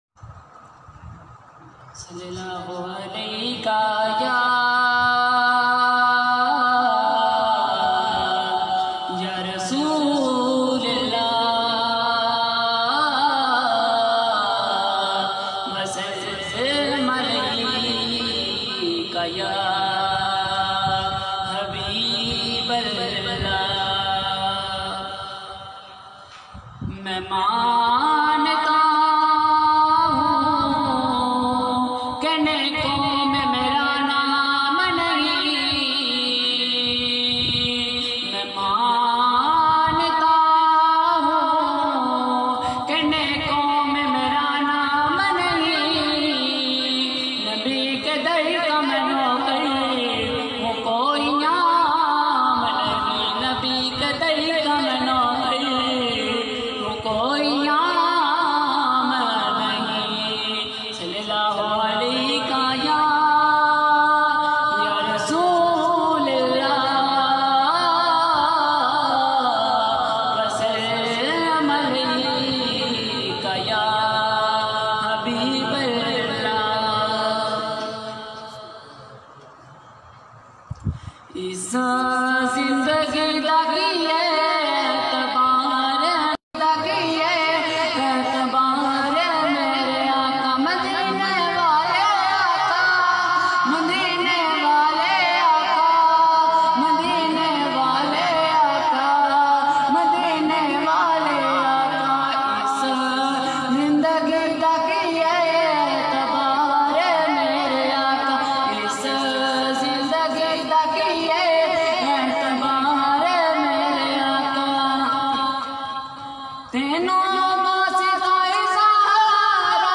Heart Touching Naat